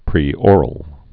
(prē-ôrəl)